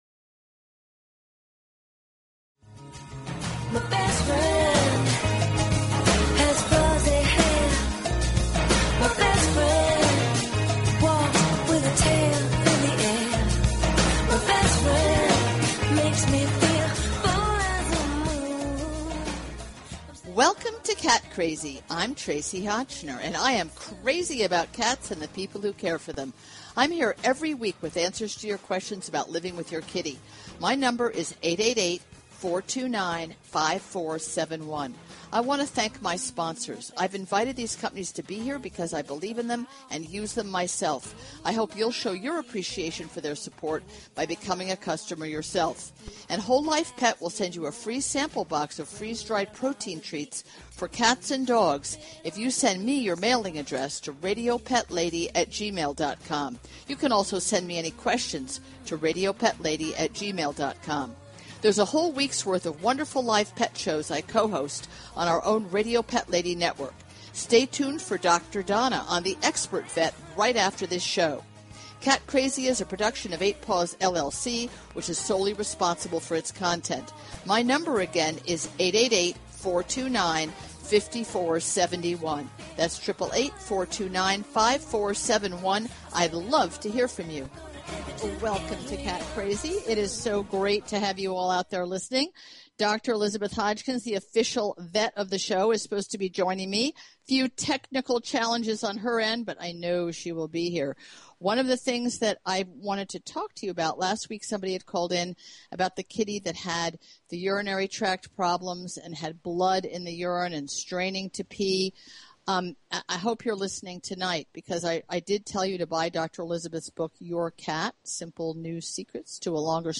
Talk Show Episode, Audio Podcast, Cat_Crazy and Courtesy of BBS Radio on , show guests , about , categorized as